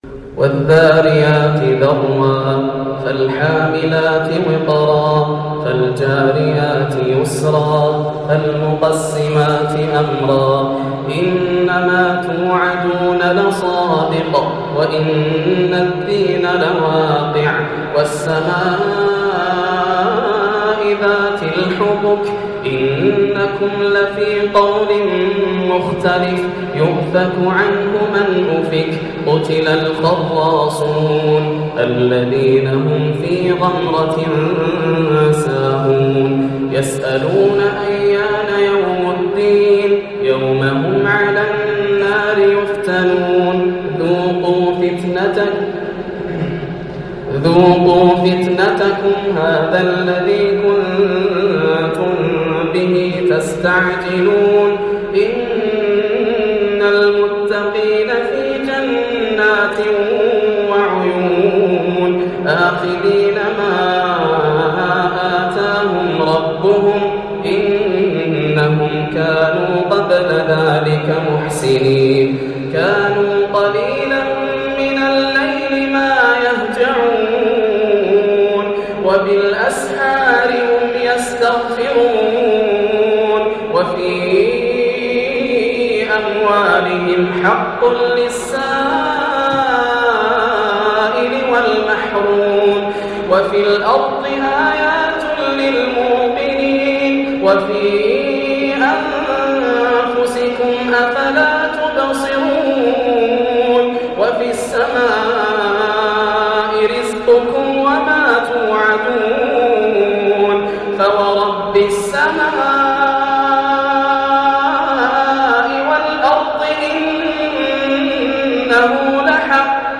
سورة الذاريات > السور المكتملة > رمضان 1430هـ > التراويح - تلاوات ياسر الدوسري